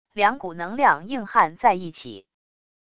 两股能量硬憾在一起wav音频